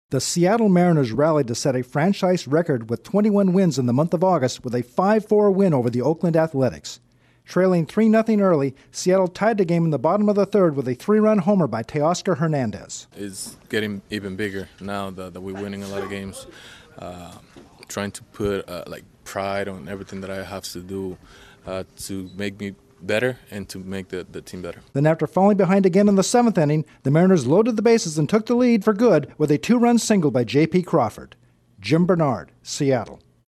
The Mariners pick up their 21st win of the month by downing the Athletics. Correspondent